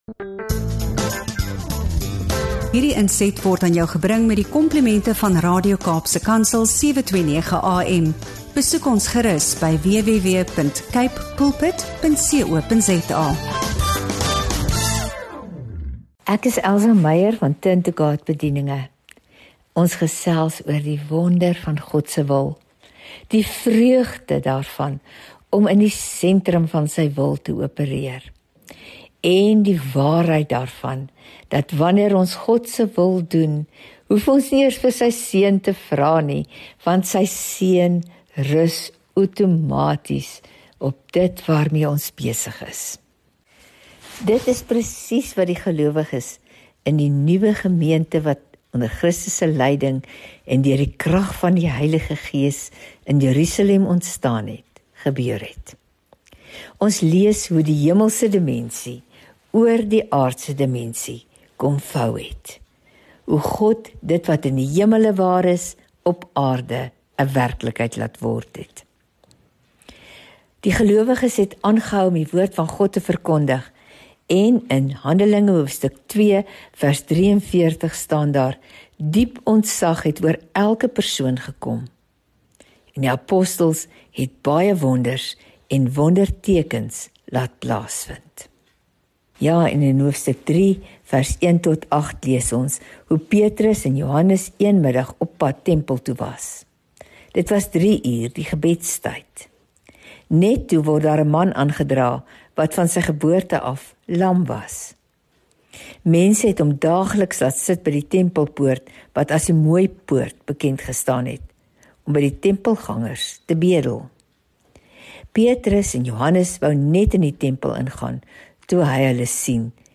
FOCUS & FOKUS DEVOTIONALS